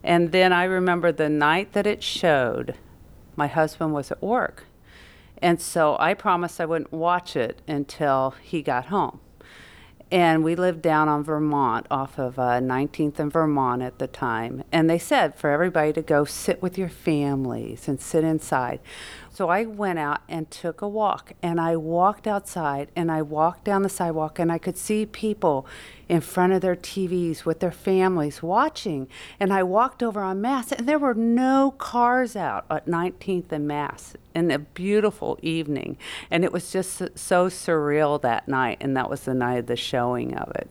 The interview was conducted at the Watkins Museum of History on June 28, 2012.
Oral History